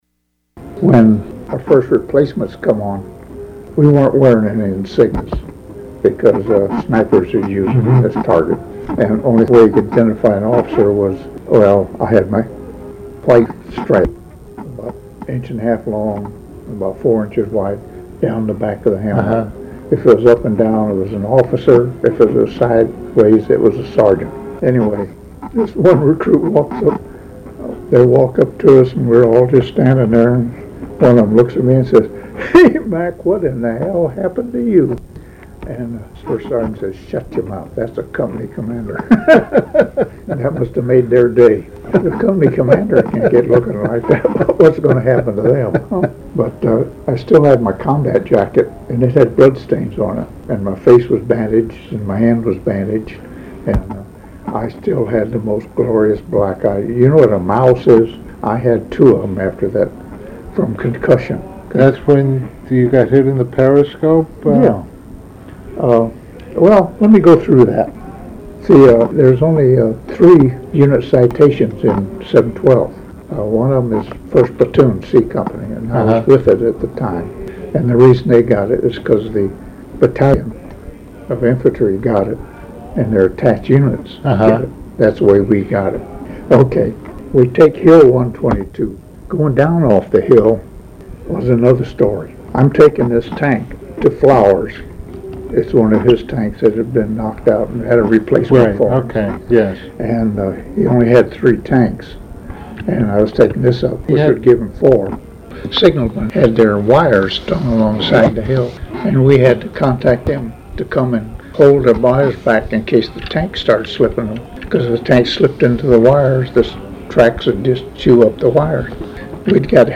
I interviewed
The interview spanned two days, and filled five 90-minute audiocassettes and about 20 minutes of a sixth.